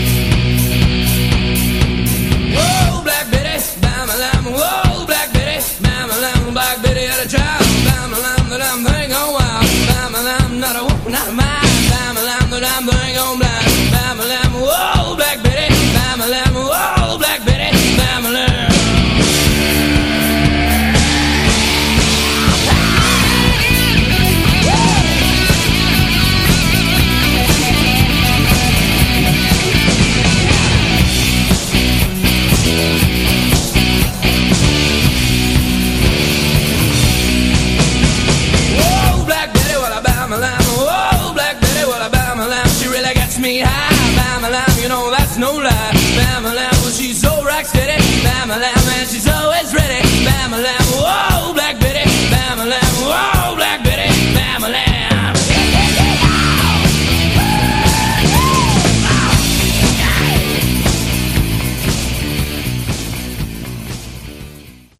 Category: Hard Rock
lead vocals, harmonica
guitar, backing vocals
bass, backing vocals
drums, backing vocals